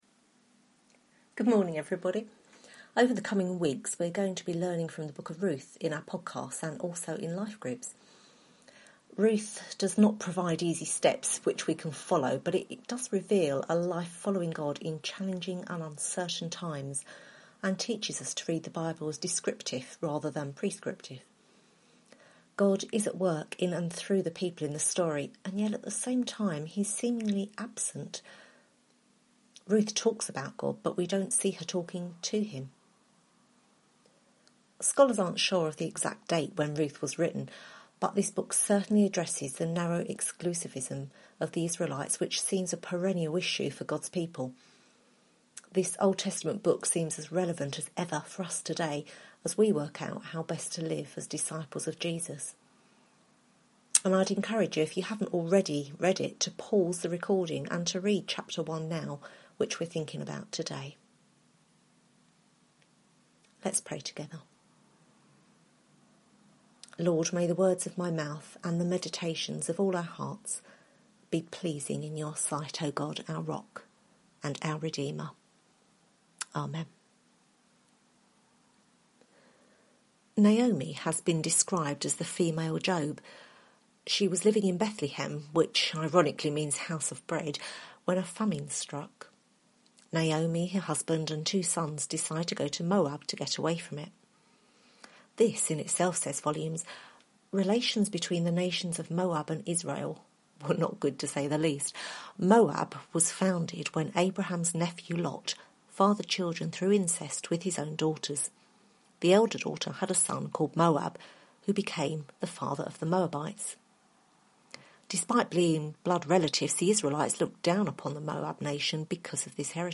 Ruth Service Type: Sunday Morning « What’s in a Name?